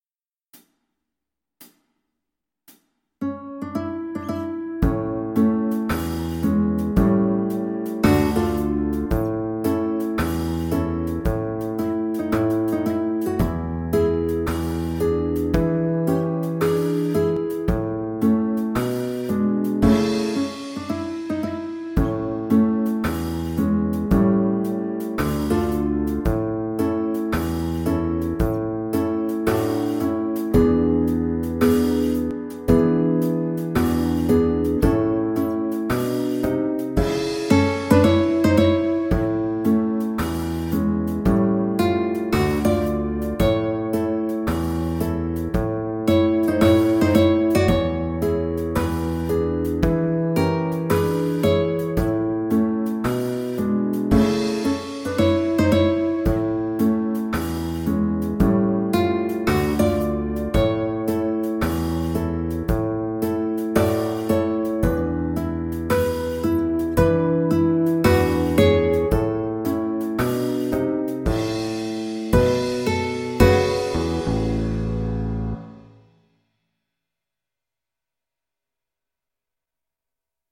Moderato = c. 112
4/4 (View more 4/4 Music)
Jazz (View more Jazz Violin Music)
Rock and pop (View more Rock and pop Violin Music)